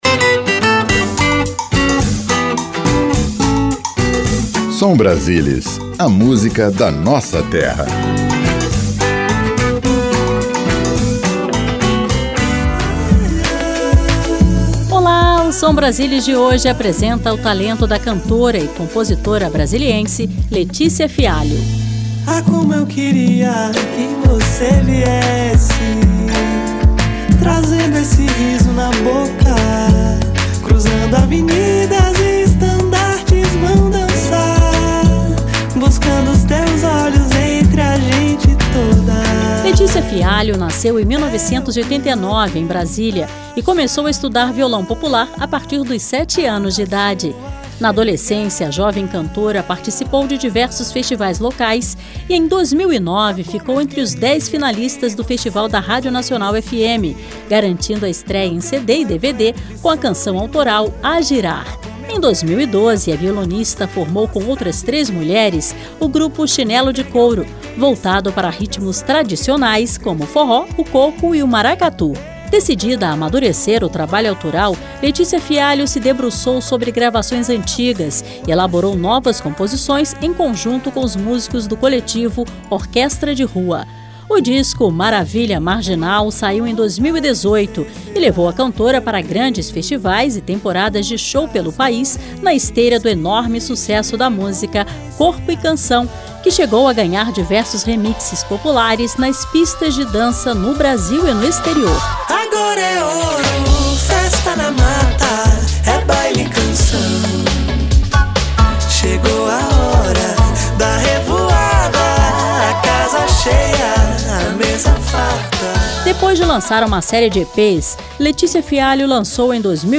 Seleção Musical:
Música Brasileira